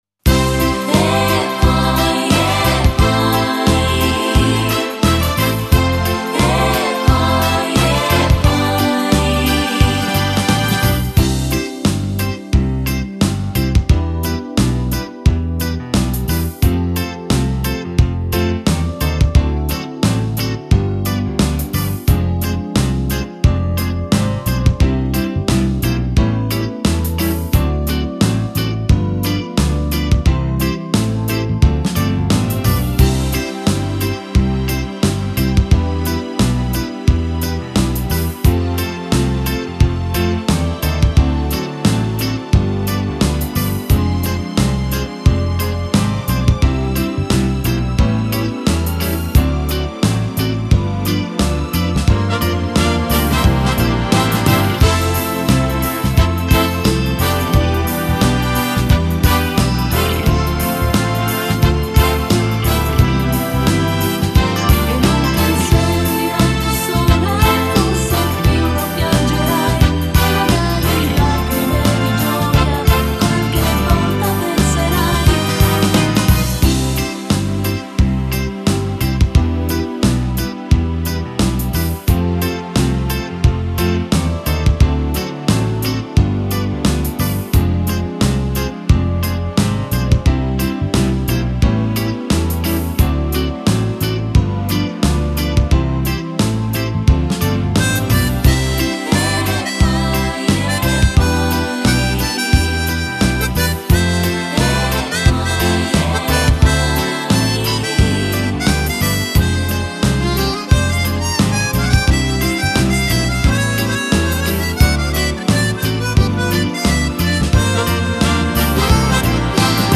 Genere: Fox trot
Scarica la Base Mp3 (2,95 MB)